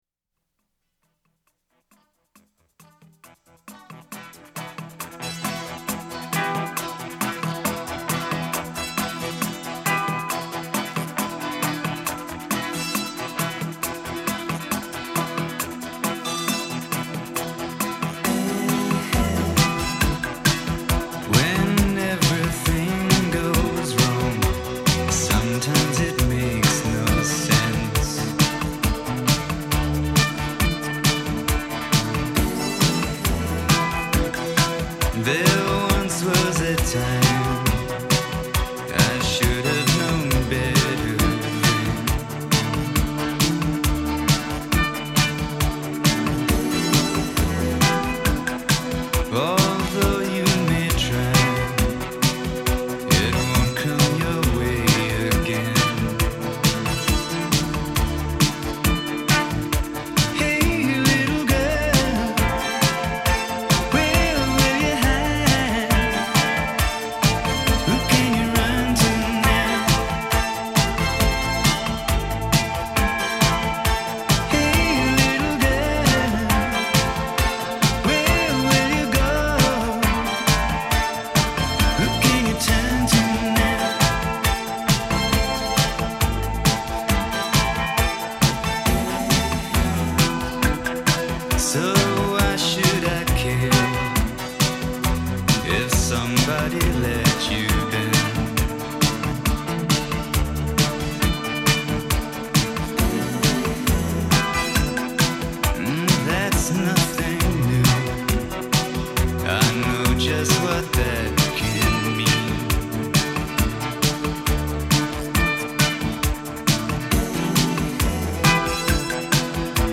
Another great atmospheric track from the same album: